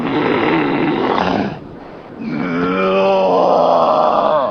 zombie.ogg